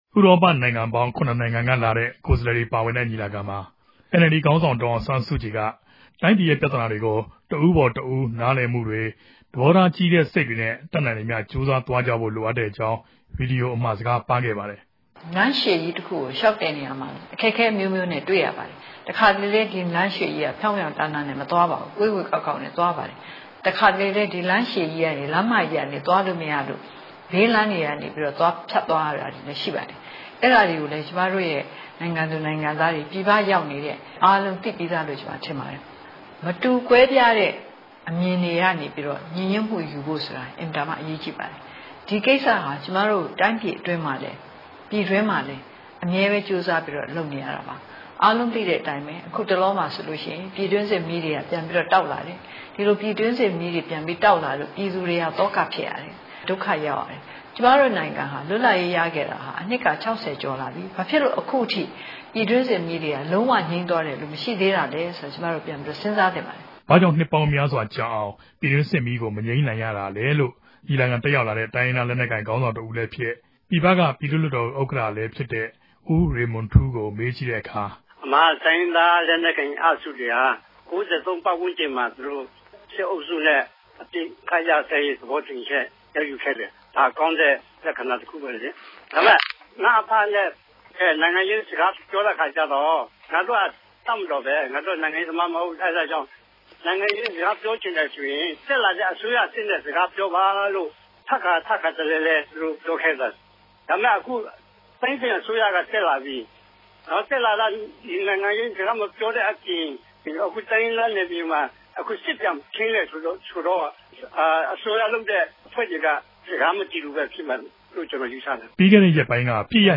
သတင်းပေးပို့ချက်။